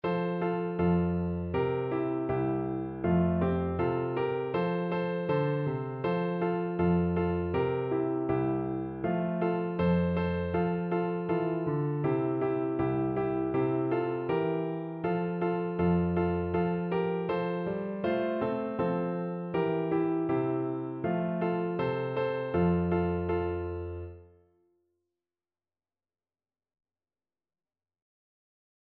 Piano version
Andante
2/4 (View more 2/4 Music)
Piano  (View more Easy Piano Music)